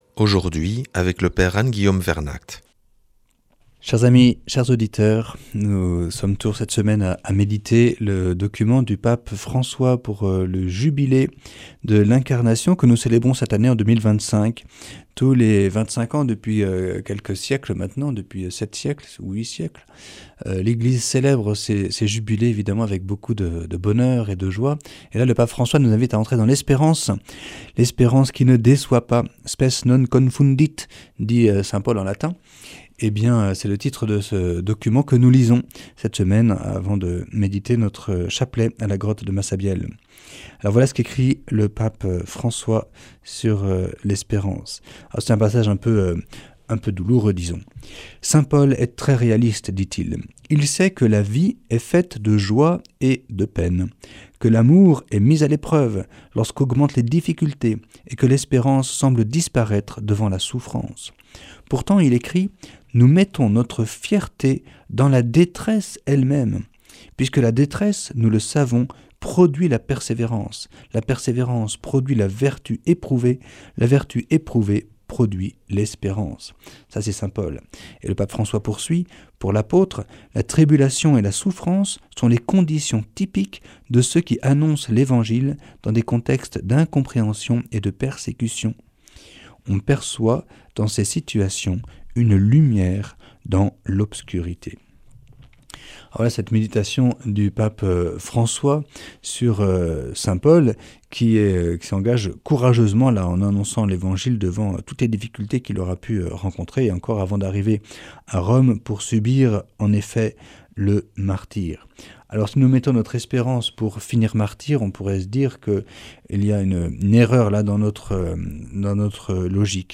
nous propose une lecture de la Bulle d’indiction du Jubilé de l’Espérance du Pape François.